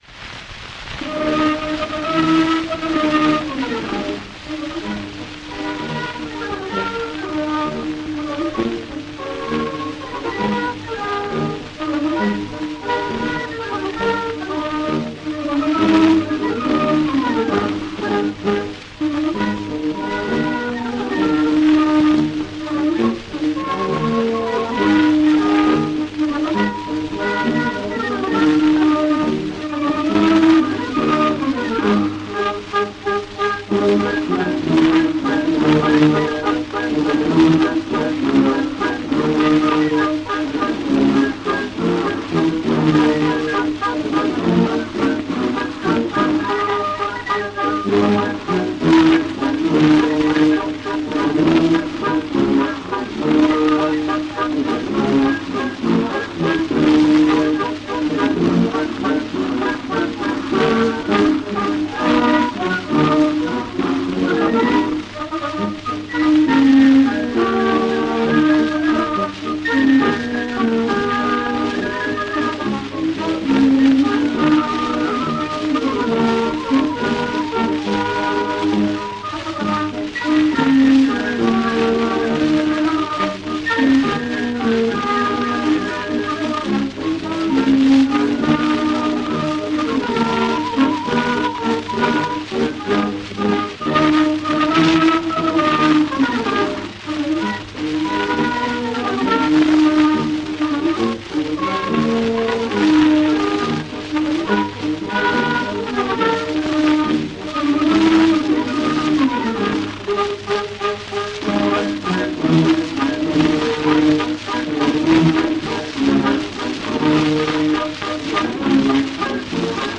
Марш, кое-как переделанный в вальс (скачать)